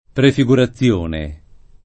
[ prefi g ura ZZL1 ne ]